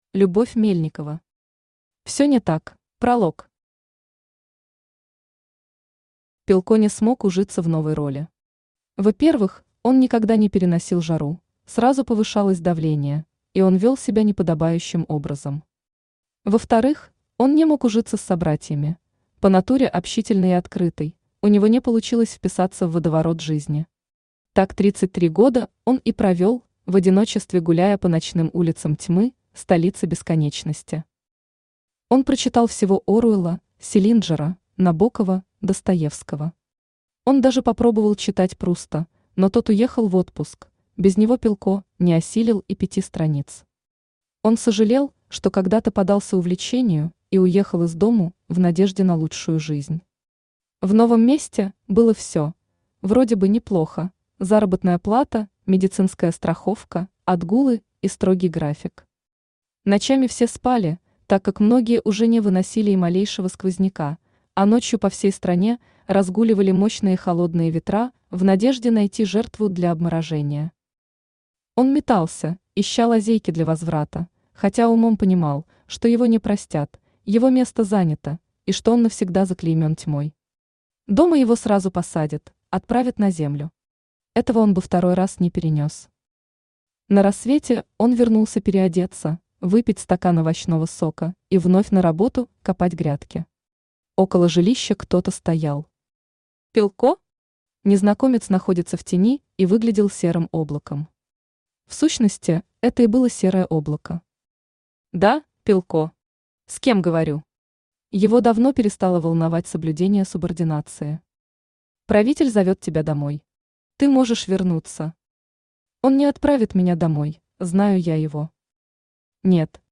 Аудиокнига Все не так | Библиотека аудиокниг
Aудиокнига Все не так Автор Любовь Мельникова Читает аудиокнигу Авточтец ЛитРес.